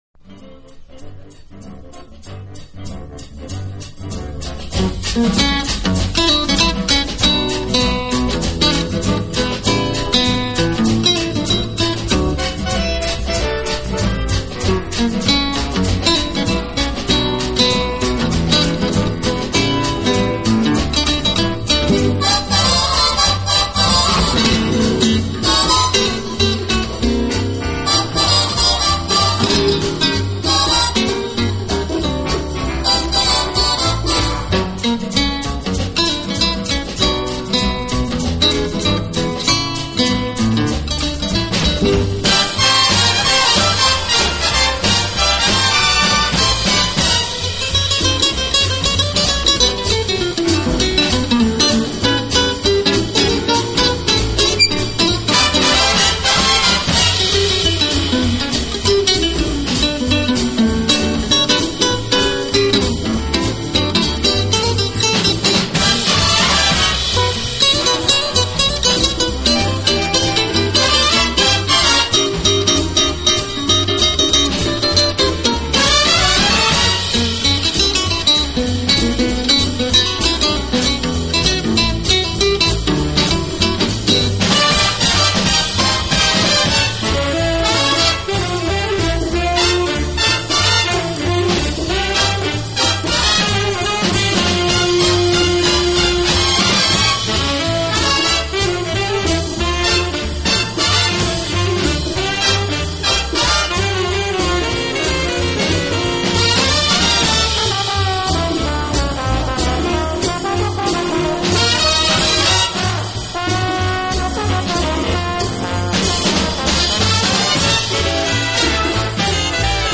巴西Bossa Nova音乐汇编，收集的全部都是以温柔娴雅著称的最难得的异国情
调的爵士乐和桑巴舞节奏的Bossa Nova音乐，就最早出版的Vo.1来讲，其中不